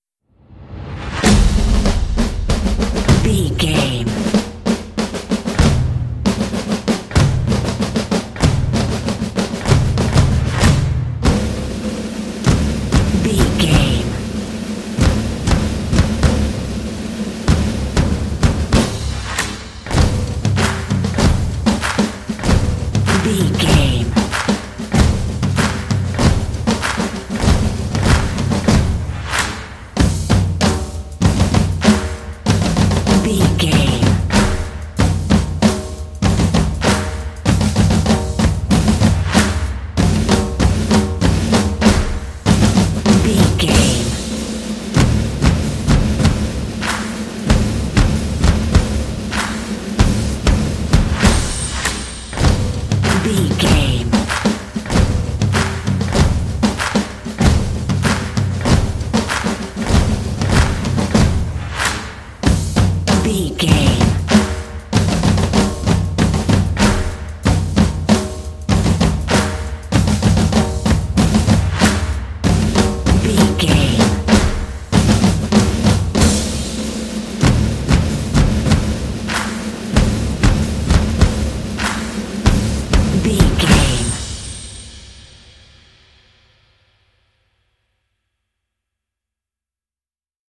This epic drumline will pump you up for some intense action.
Epic / Action
Atonal
driving
motivational
determined
drums
percussion